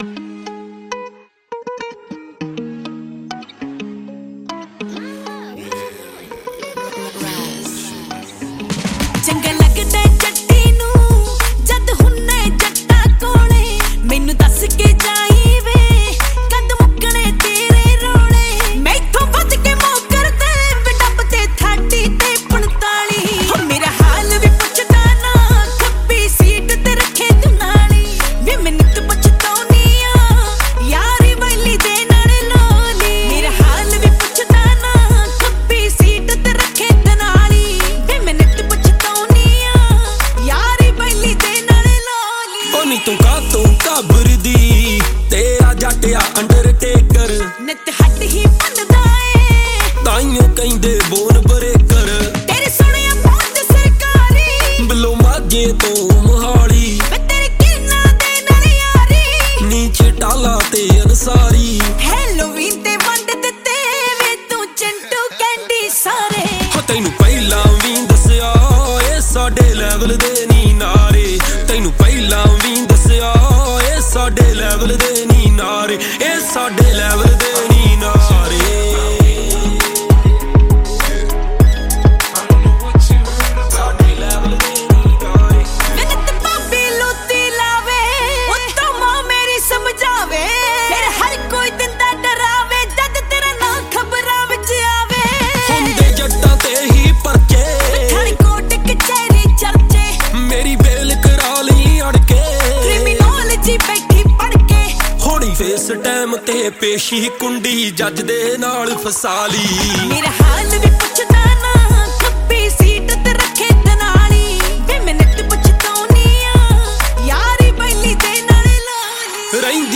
Category: Punjabi